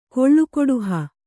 ♪ koḷḷu koḍuha